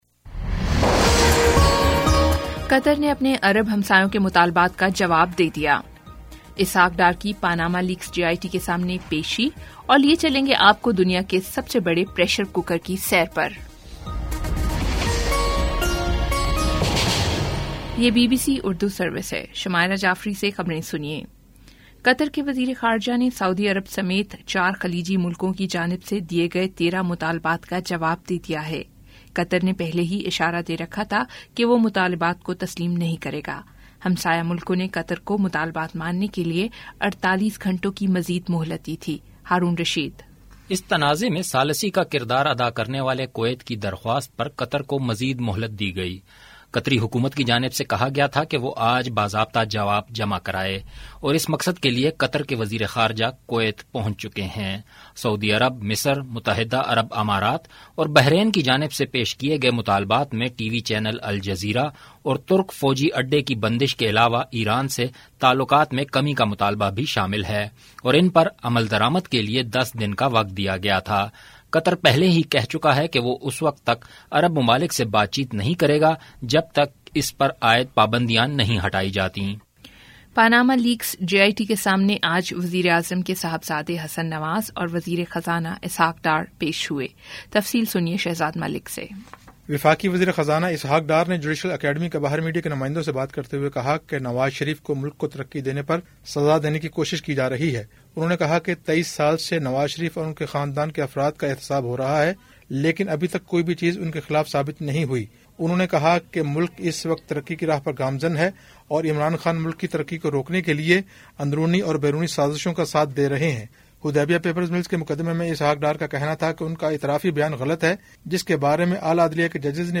جولائی 03 : شام سات بجے کا نیوز بُلیٹن